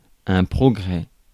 Ääntäminen
IPA: /pʁo.ɡʁɛ/